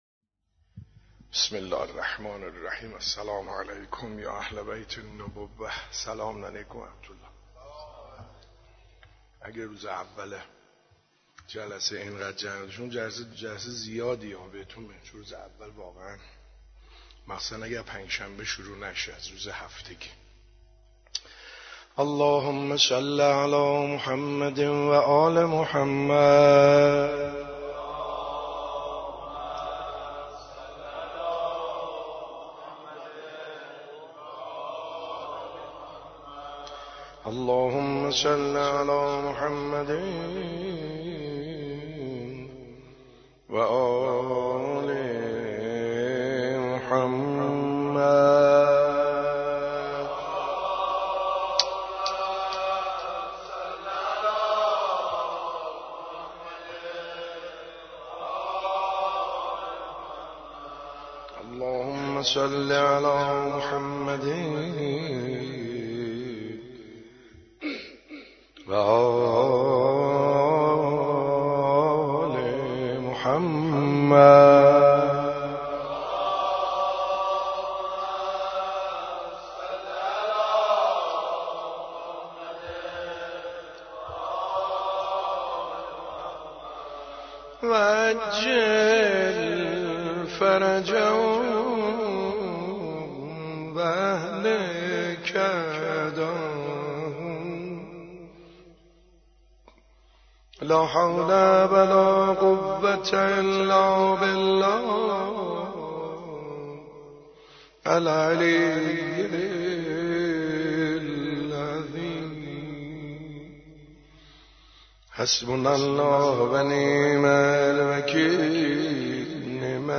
روضه حضرت زهرا(س) /حاج سعید حدادیان/فاطمیه 91